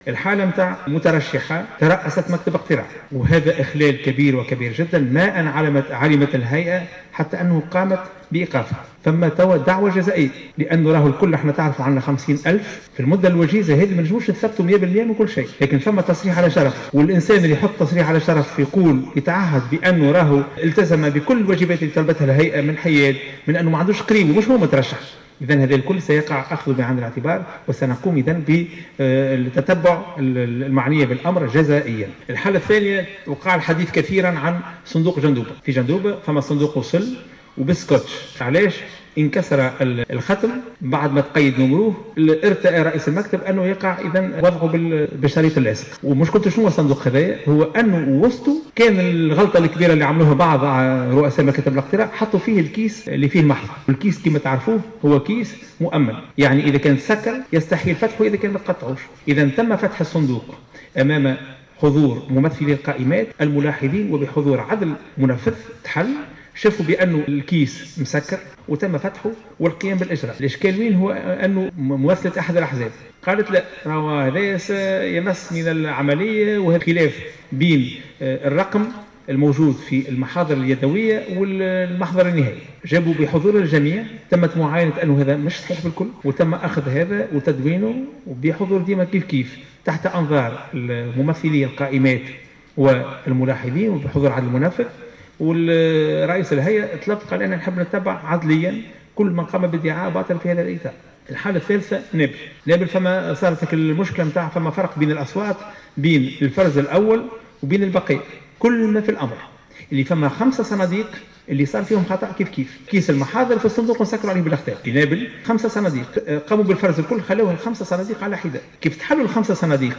أعلن رئيس الهيئة العليا المستقلة للانتخابات، شفيق صرصار في ندوة صحفية بالمركز الإعلامي بقصر المؤتمرات بالعاصمة اليوم الأربعاء، أن الهيئة ستتخذ جميع الإجراءات ضد مترشحة ترأست مكتب اقتراع بإحدى الدوائر، وأنها ستقوم بتتبعها جزائيا،كما فسر صرصار الإشكالات المتعلقة خاصة بكل من جندوبة ونابل.